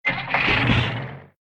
KART_Engine_start_2.ogg